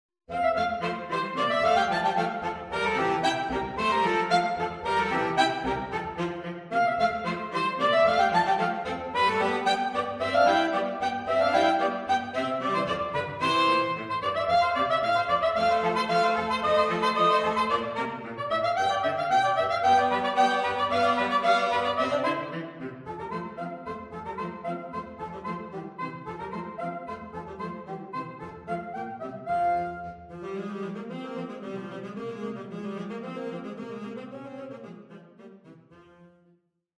These flexible pieces can be played as either Duets or Trios